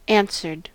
Ääntäminen
Ääntäminen US Tuntematon aksentti: IPA : /ænsɜːd/ Haettu sana löytyi näillä lähdekielillä: englanti Answered on sanan answer partisiipin perfekti.